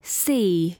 Звуки букв английского алфавита
Произношение четкое, без фоновых шумов.
Cc si